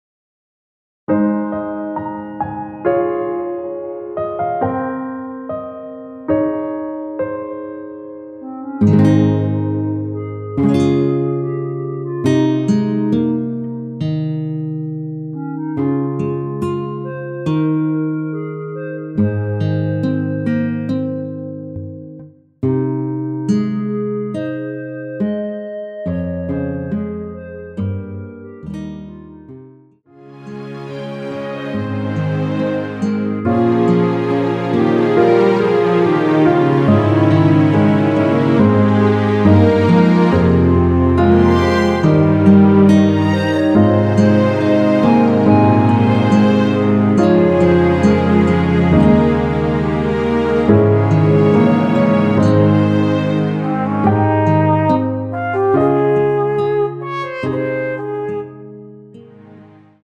원키에서(-1)내린 멜로디 포함된 MR입니다.
Ab
앞부분30초, 뒷부분30초씩 편집해서 올려 드리고 있습니다.
중간에 음이 끈어지고 다시 나오는 이유는